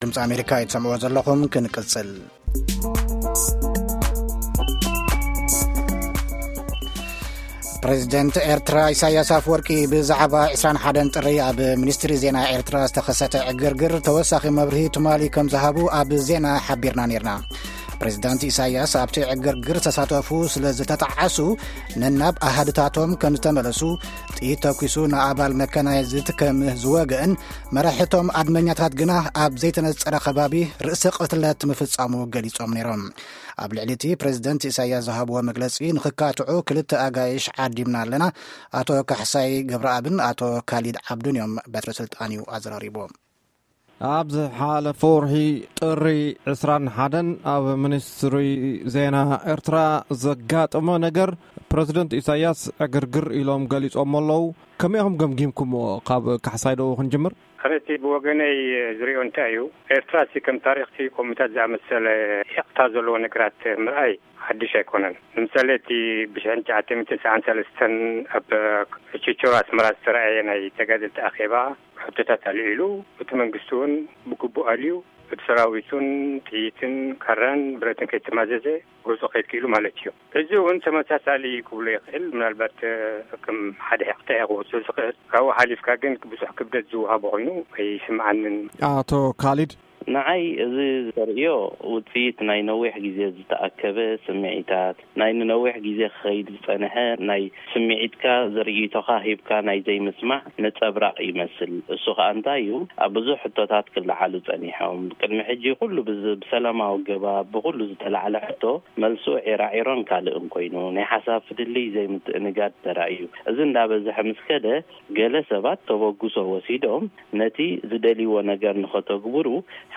Eritrea-Debate